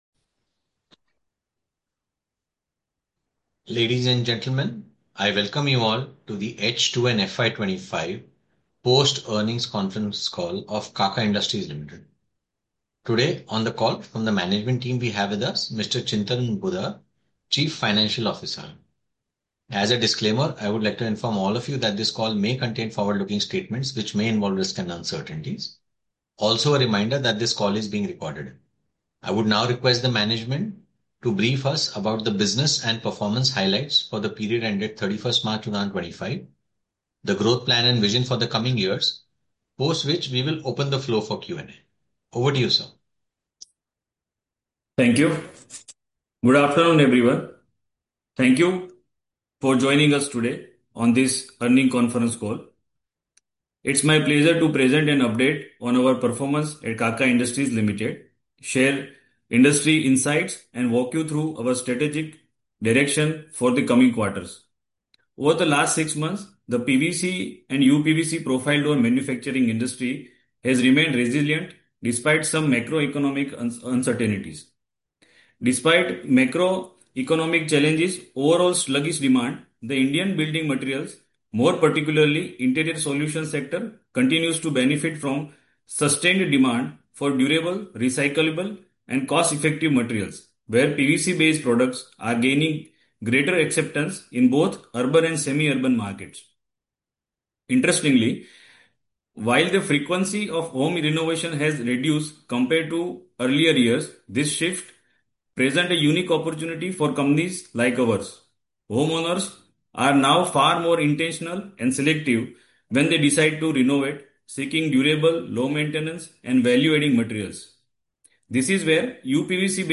Kaka Industries Limited-H2 FY 24-25 Post Earning Conference Call
Kaka-Industries-Limited-H2-FY-24-25-Post-Earning-Conference-Call.mp3